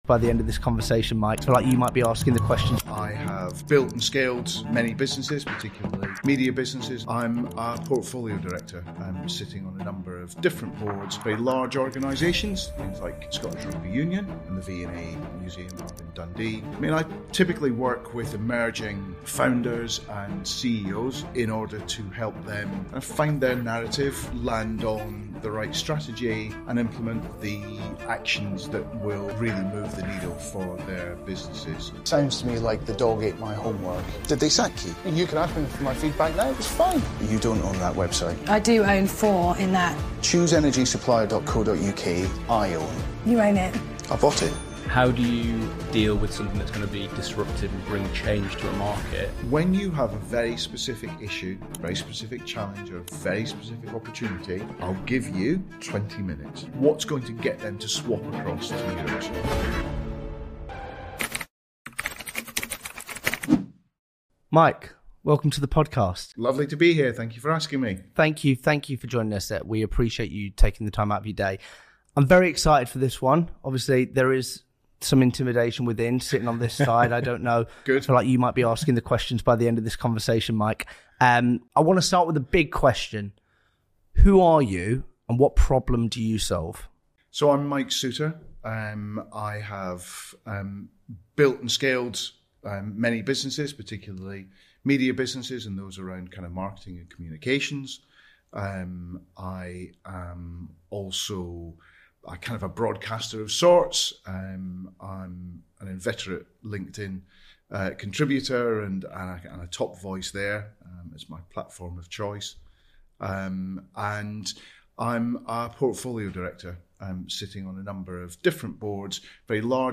In this episode of We Have A Meeting, we sit down with Mike Soutar — co-founder of Shortlist Media, board director at Scottish Rugby and the V&A Dundee, and the man behind 13 seasons of The Apprentice’s most intense interviews.